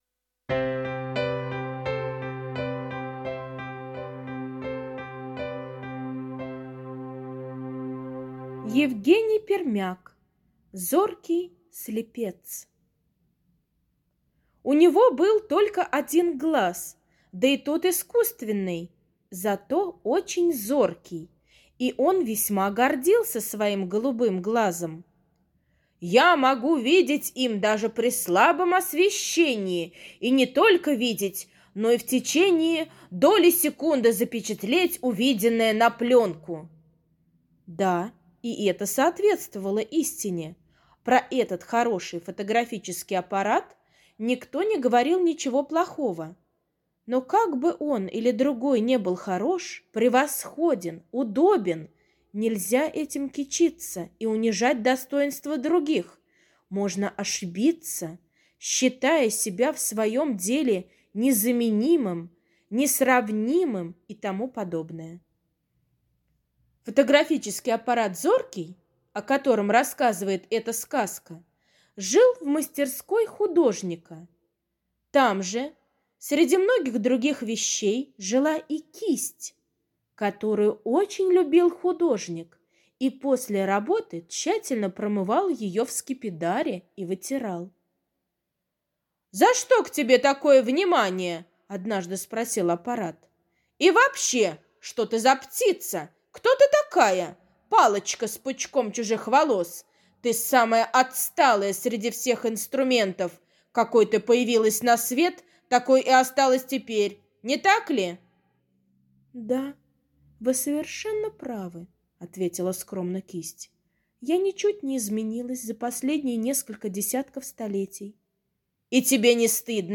Зоркий слепец — аудиосказка Пермяка Е. Сказка про разговор фотоаппарат и кисти художника.